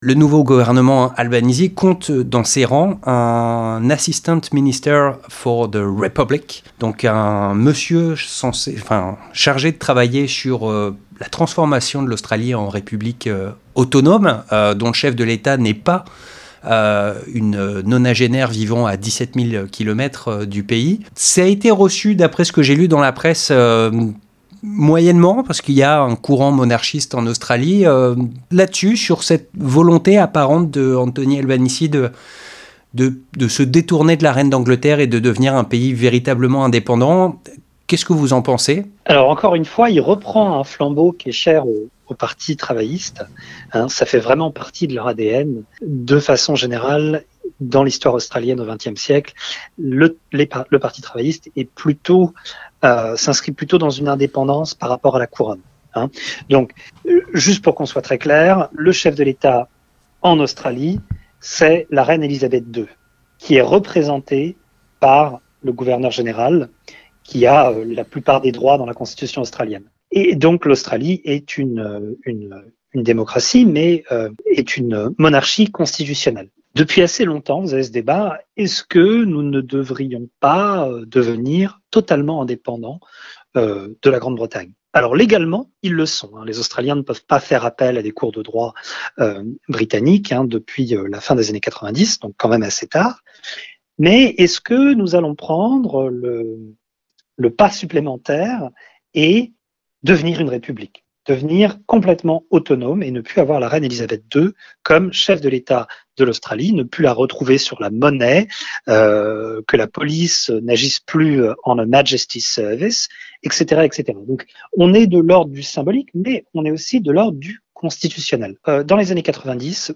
Nous en parlons avec l'historien